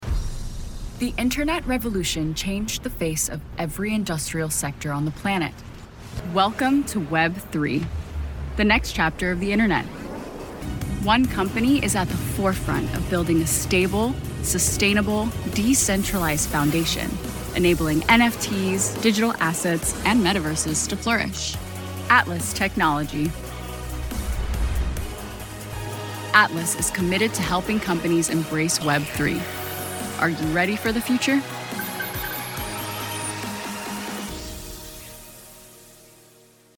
Female Voice Over, Dan Wachs Talent Agency.
Vivacious, Enthusiastic, Bilingual
Corporate